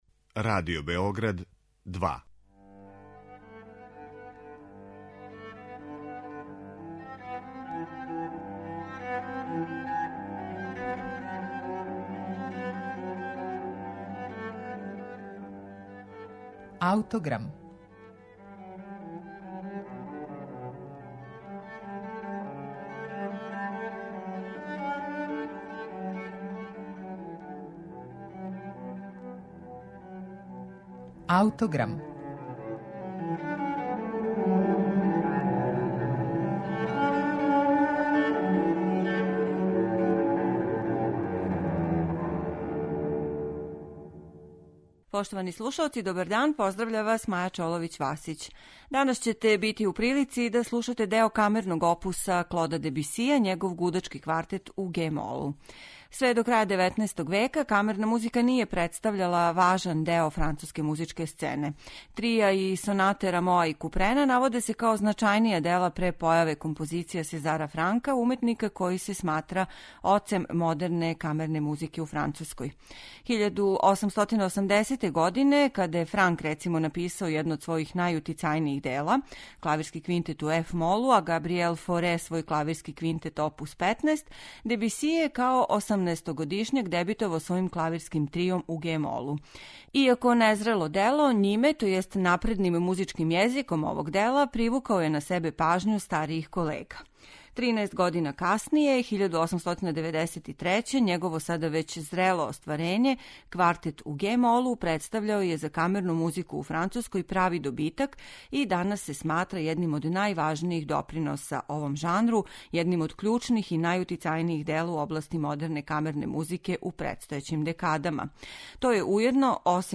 Дебисијев Гудачки квартет у ге-молу